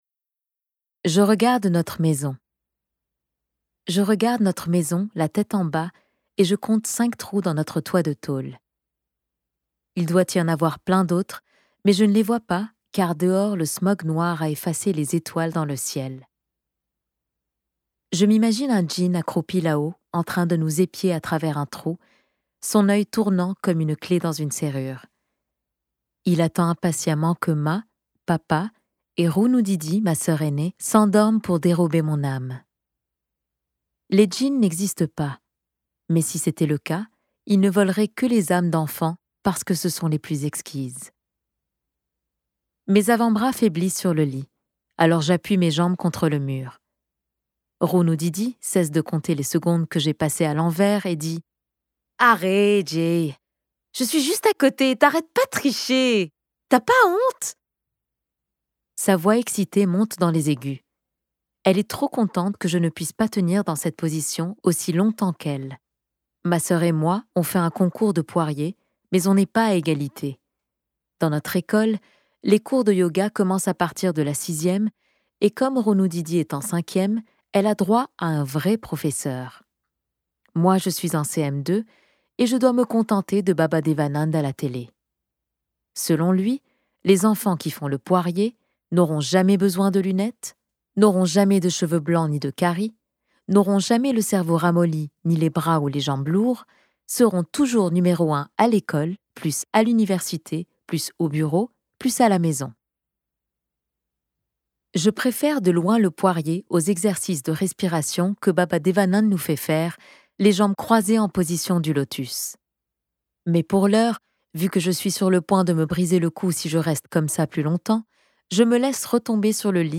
Audiobook DM